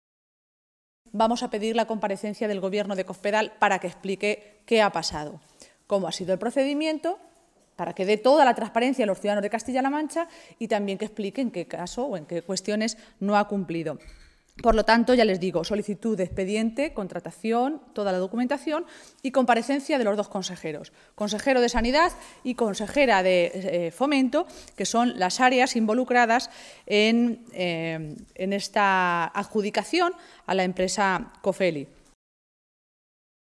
Maestre se pronunciaba de esta manera esta mañana, en una comparecencia ante los medios de comunicación, en Toledo, en la que recordaba que Cañizares había dicho que Cospedal había “limpiado” el PP de políticos corruptos.
Cortes de audio de la rueda de prensa